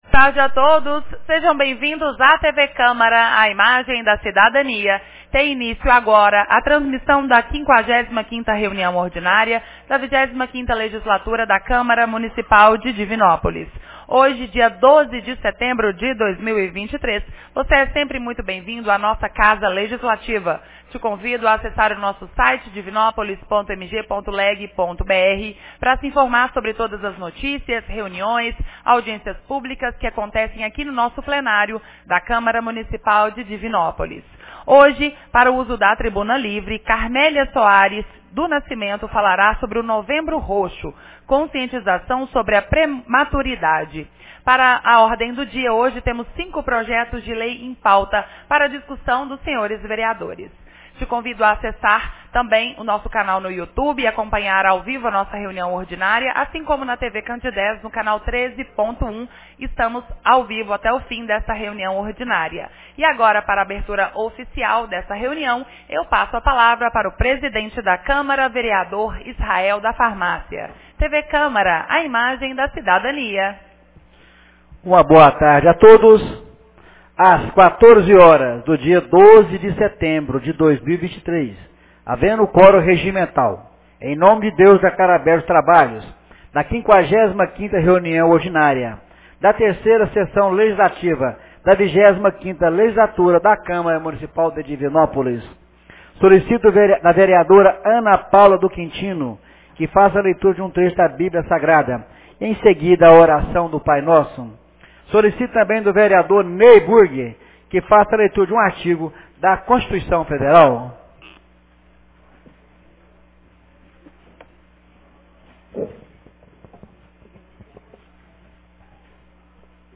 55ª Reunião Ordinária 12 de setembro de 2023